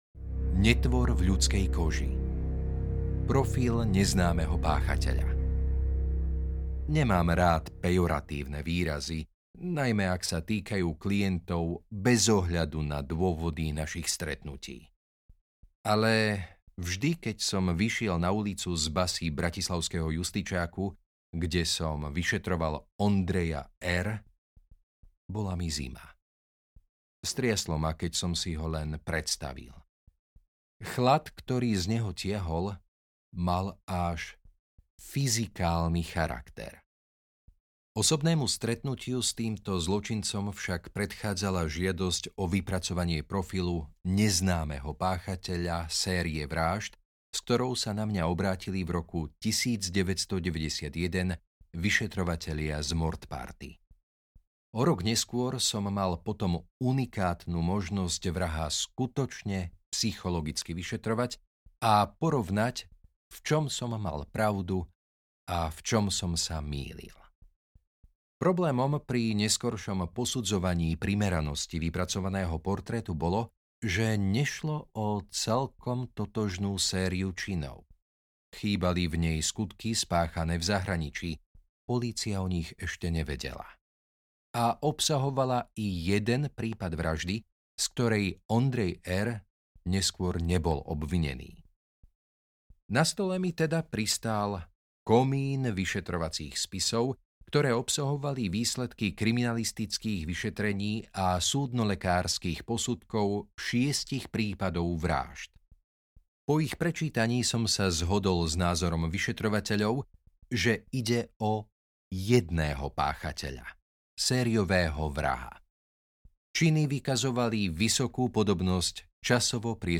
Zlo audiokniha
Ukázka z knihy